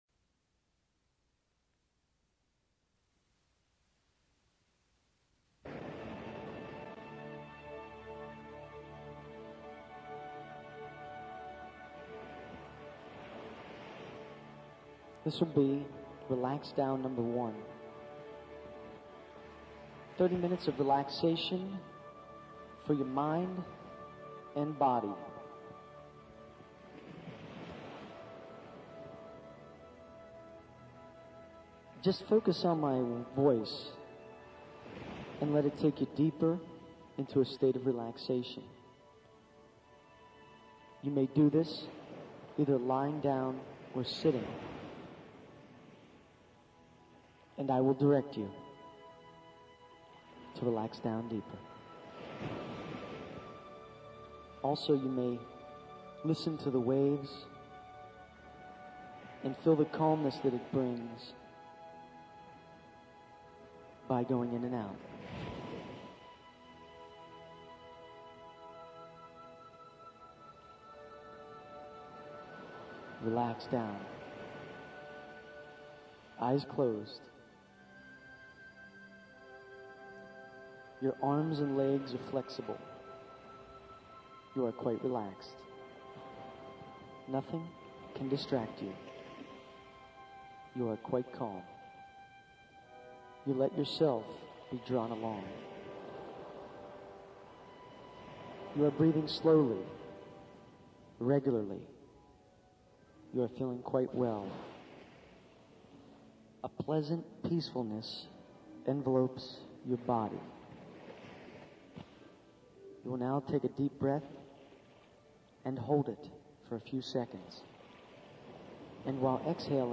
This is the Guided Meditation MP3 for this series.